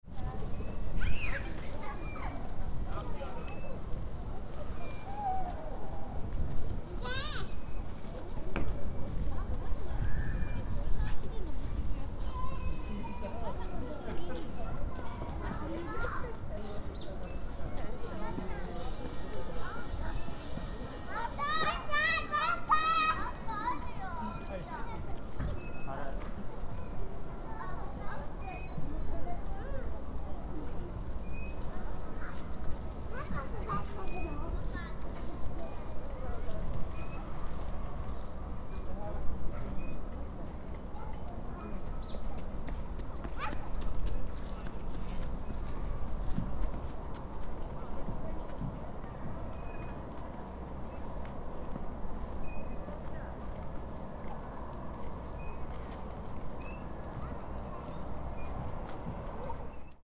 After the parade of O-waraji had passed, some families were playing cheerfully at the park. ♦ Occasionally, some birds were twittering.